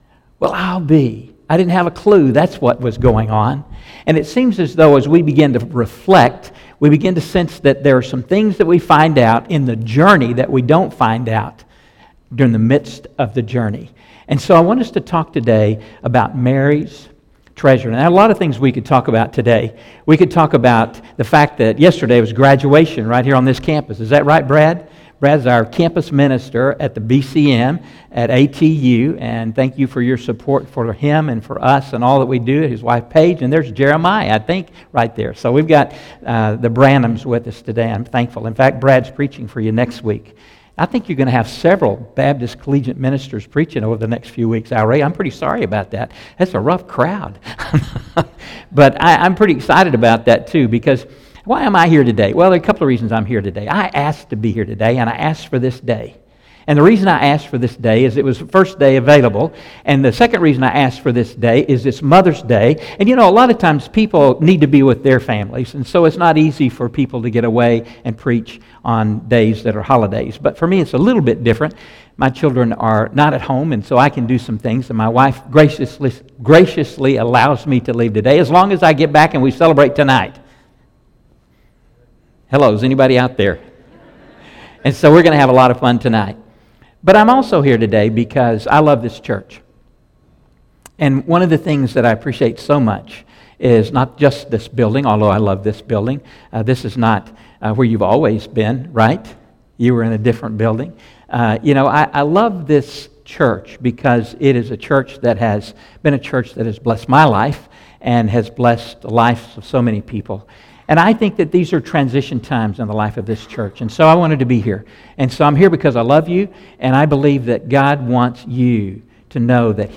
by Office Manager | May 9, 2016 | Bulletin, Sermons | 0 comments